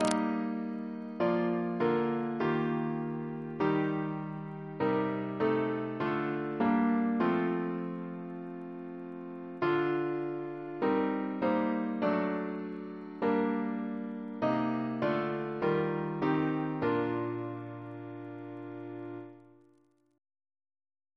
Double chant in B♭ Composer: Francis Jackson (1917-2022) Reference psalters: RSCM: 78